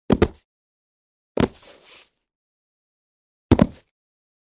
Звуки падения
1. Звук удара человека о бетон или асфальт n2. Как звучит падение человека на бетон или асфальт n3. Шум от падения тела на бетон или асфальт n4. Звуковые эффекты при падении человека на твердую поверхность n5. Характерный звук падения на бетон или асфальт